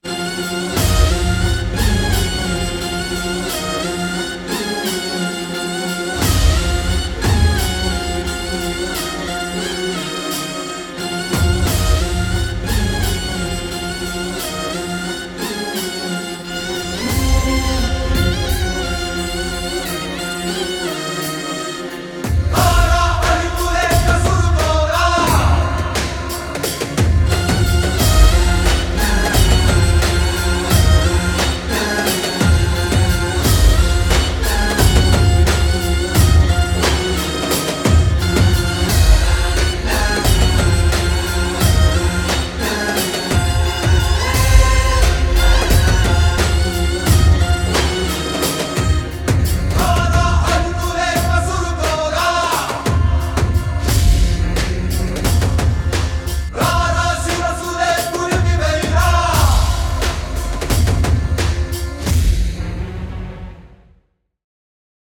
in high quality without dialogues and fight sounds
Devotional Fight Scene BGM